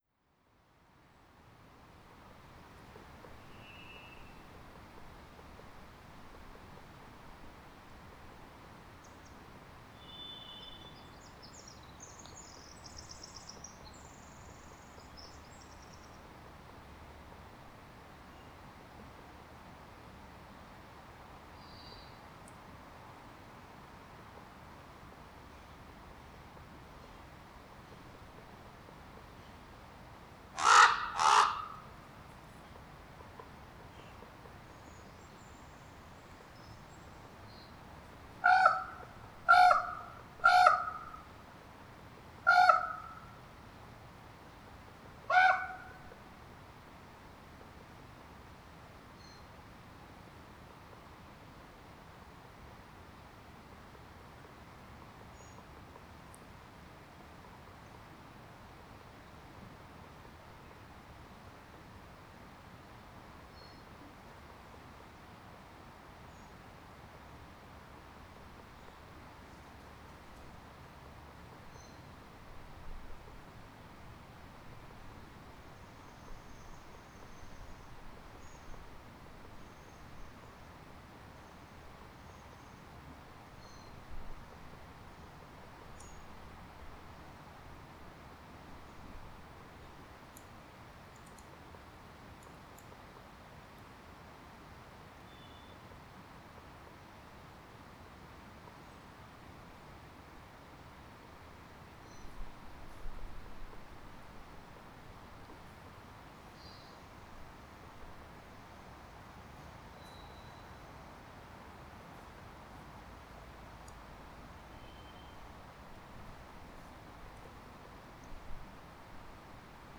Recordings from the trail through the beautiful old growth forest at Fillongley Provincial Park on Denman Island with the bird- and creek-sounds in early spring 2022.
1. Bird sounds – Thrush, Raven, Wren, Woodpecker (creek)
There were sounds captured with the recorder that were not heard or were difficult to hear with the human ear – including the soft woodpecker tapping sounds and the wing-beats, which sound almost like a bird bathing.